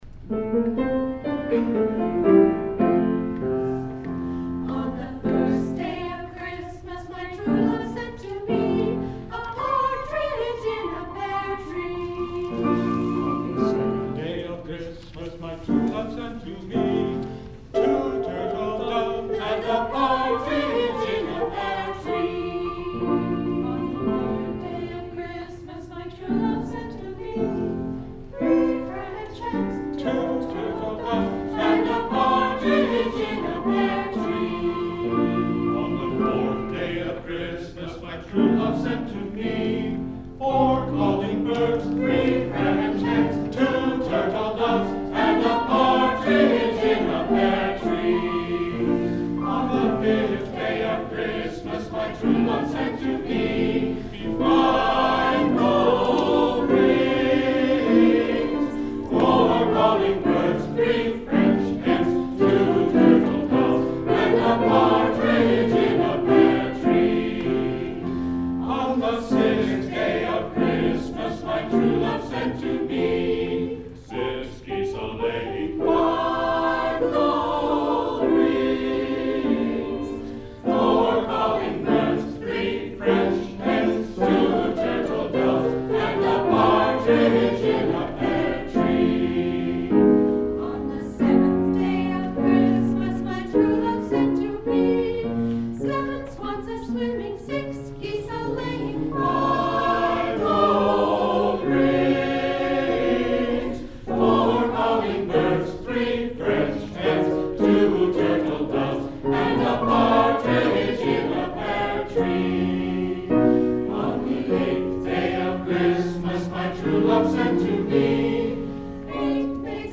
Wrangell’s Community Chorale music group held its holiday concert in the Nolan Center Sunday. The volunteer choir sang traditional and contemporary holiday tunes for the community.
Wrangell Community Chorale performance: